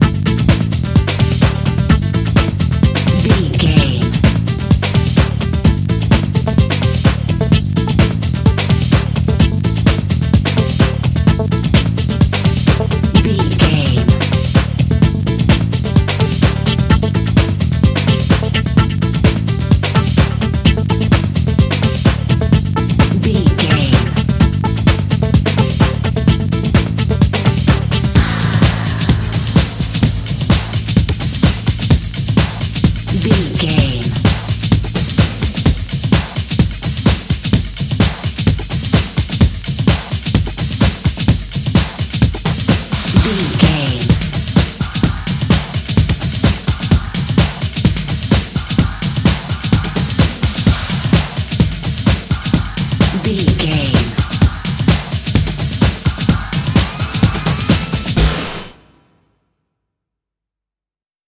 Aeolian/Minor
D
Fast
drum machine
synthesiser
electric piano
90s
Eurodance